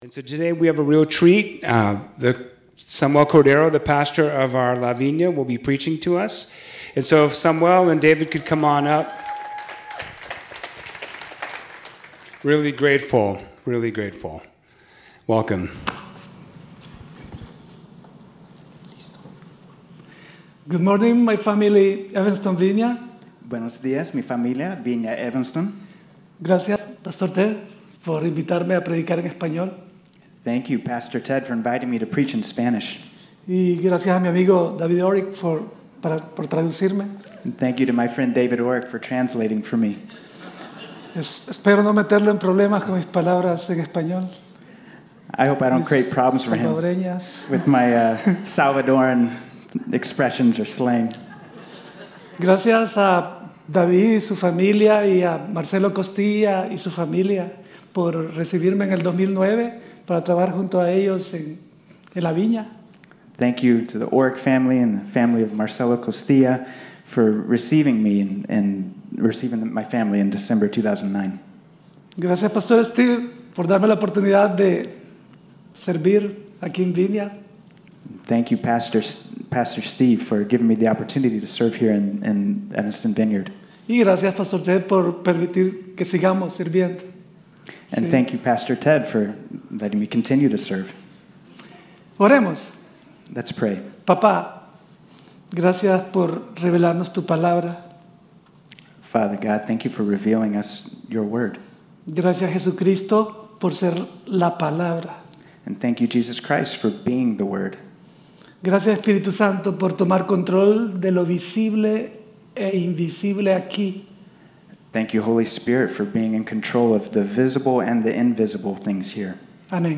preaches